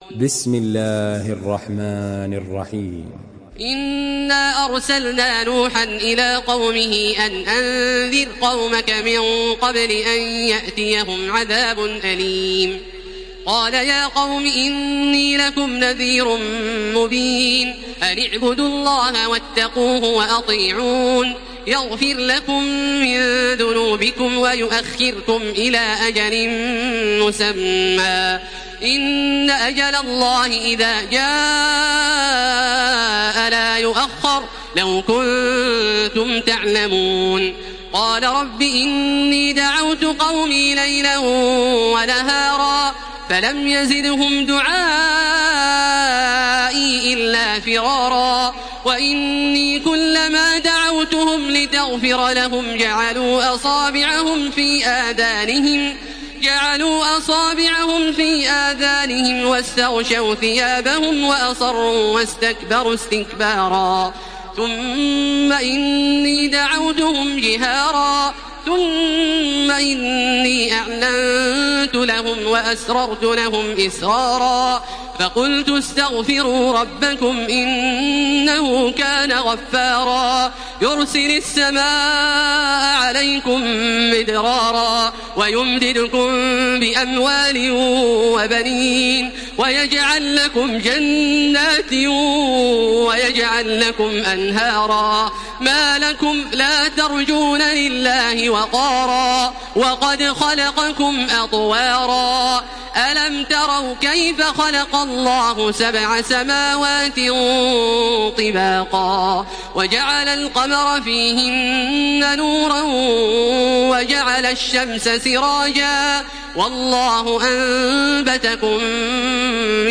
Surah Nuh MP3 by Makkah Taraweeh 1433 in Hafs An Asim narration.
Murattal Hafs An Asim